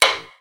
Taiko no Tatsujin 2020 Version - Common Sound Effects
Katsu.wav